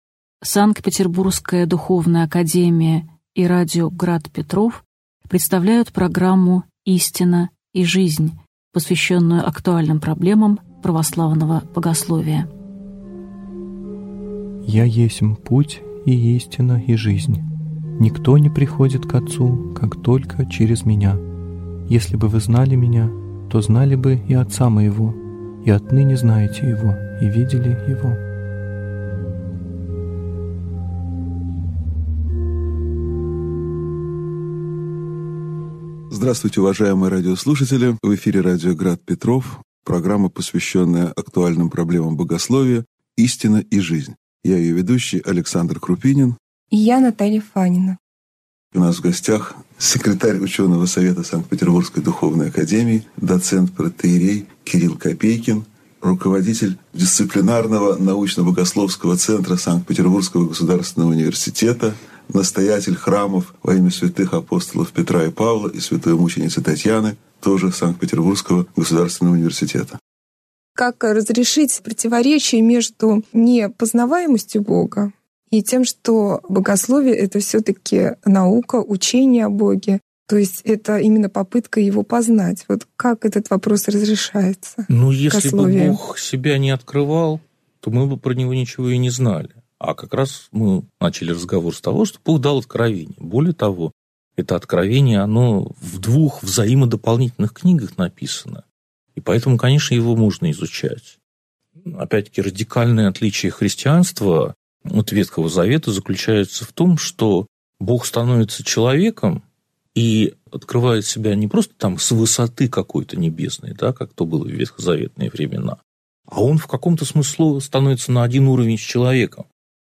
Аудиокнига Истина и Жизнь (часть 2) | Библиотека аудиокниг